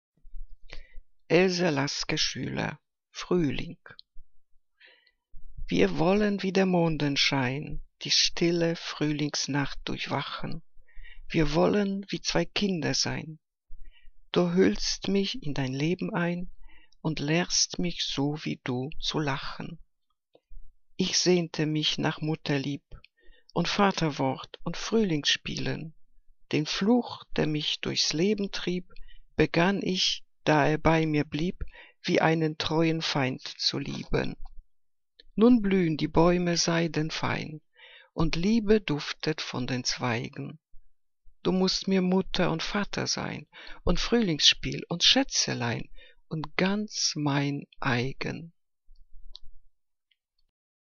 Liebeslyrik deutscher Dichter und Dichterinnen - gesprochen (Else Lasker-Schüler)